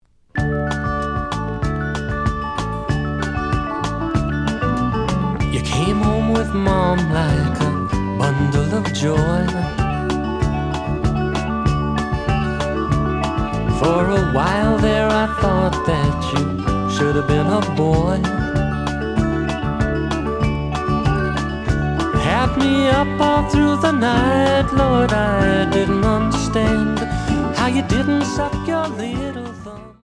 The audio sample is recorded from the actual item.
●Genre: Folk / Country